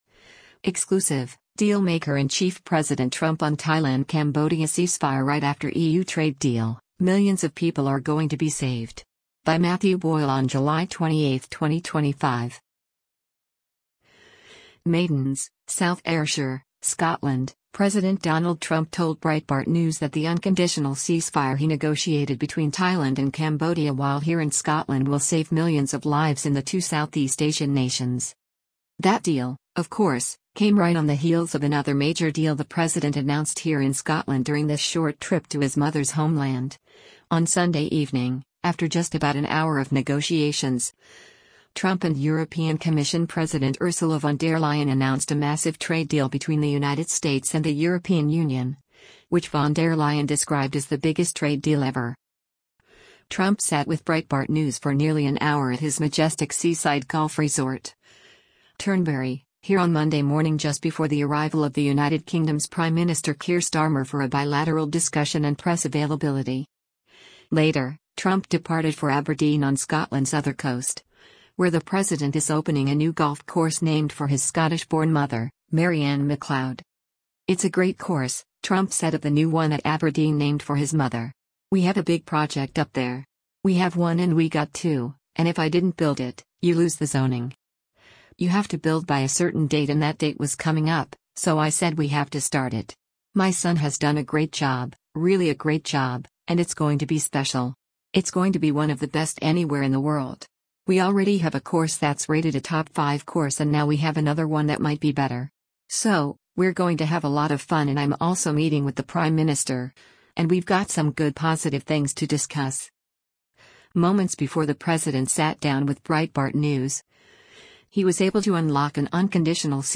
Trump sat with Breitbart News for nearly an hour at his majestic seaside golf resort, Turnberry, here on Monday morning just before the arrival of the United Kingdom’s Prime Minister Keir Starmer for a bilateral discussion and press availability.
Much more from President Trump’s latest exclusive interview with Breitbart News is forthcoming.